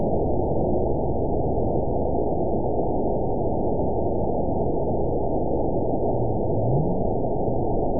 event 911083 date 02/09/22 time 21:48:41 GMT (3 years, 3 months ago) score 9.36 location TSS-AB01 detected by nrw target species NRW annotations +NRW Spectrogram: Frequency (kHz) vs. Time (s) audio not available .wav